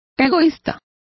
Complete with pronunciation of the translation of selfish.